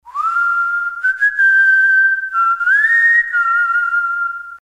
• Качество: 320, Stereo
спокойные
приятные
Мелодичный свист для оповещений